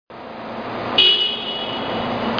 7020系警笛
クラクションに非ず。
近鉄はこんな感じの音の警笛が多めです。